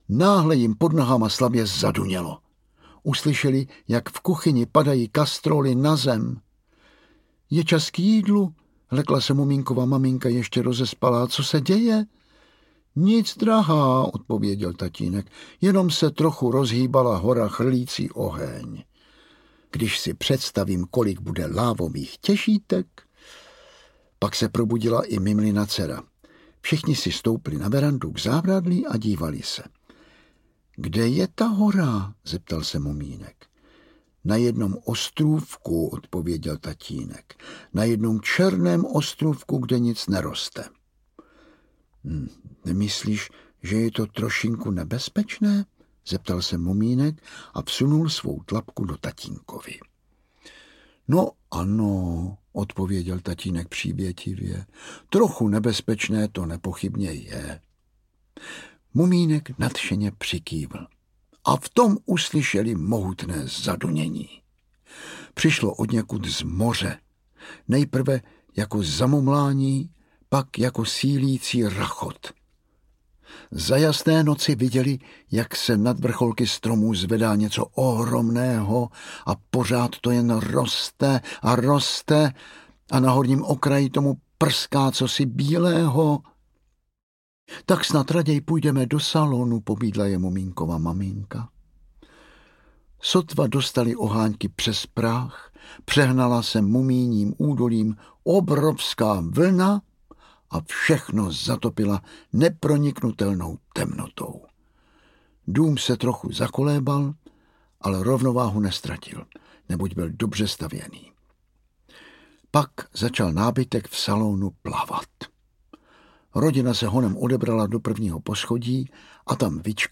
Bláznivé léto audiokniha
Ukázka z knihy
Vyrobilo studio Soundguru.